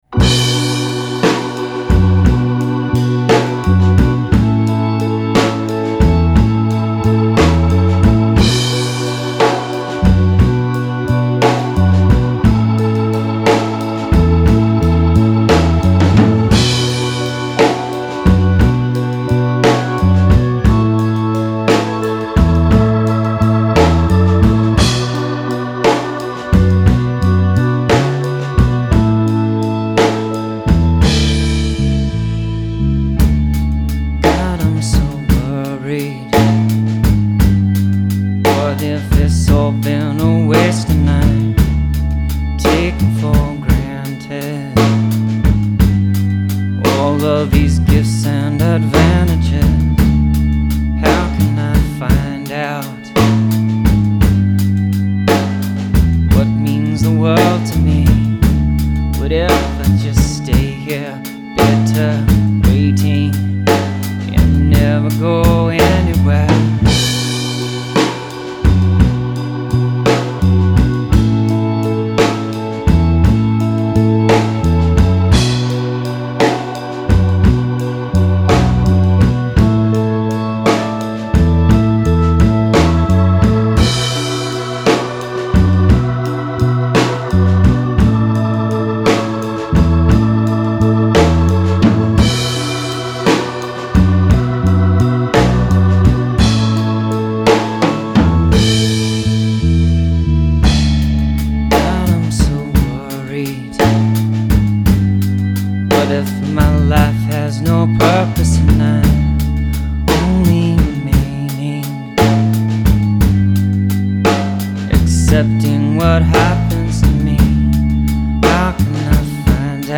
too mellow/downer